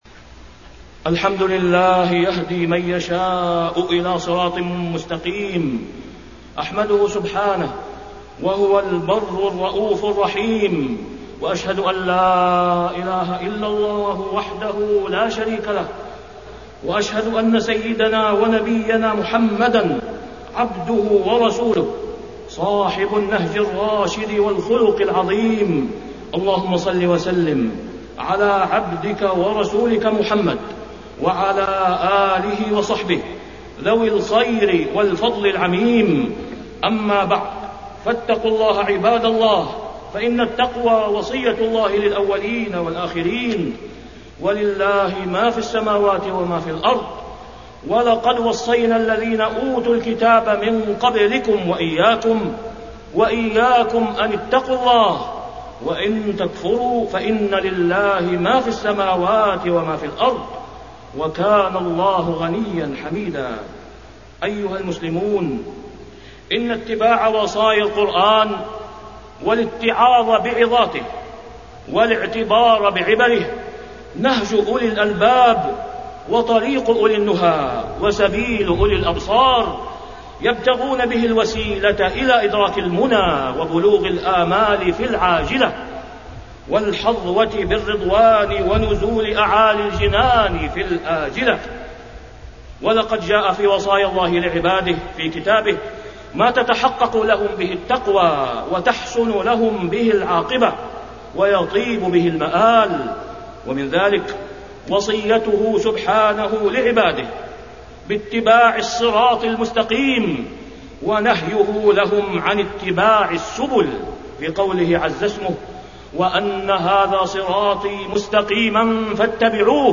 تاريخ النشر ١ رجب ١٤٢٩ هـ المكان: المسجد الحرام الشيخ: فضيلة الشيخ د. أسامة بن عبدالله خياط فضيلة الشيخ د. أسامة بن عبدالله خياط التأثر بالقرآن